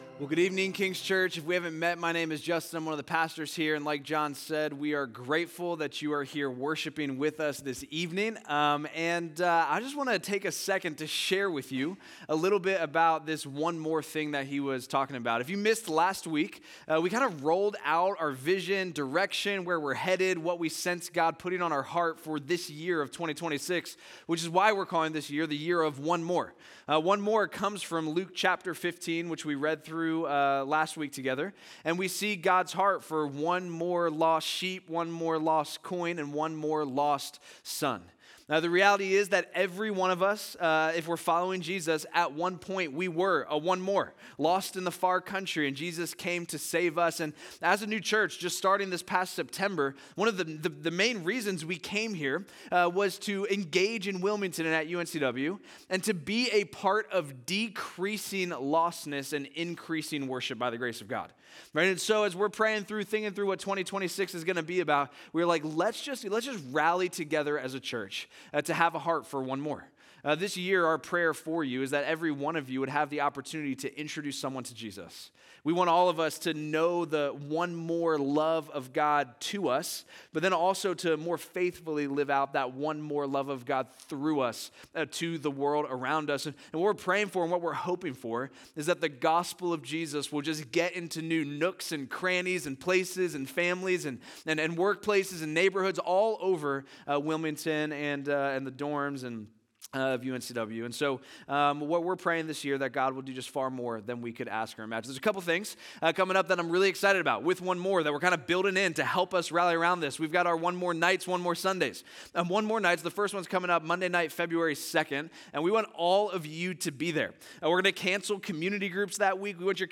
1:19:25 Sermon (Audio).m4a